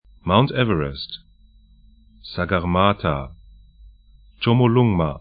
'maʊnt 'ɛvərɛst tʃomo'lʊŋma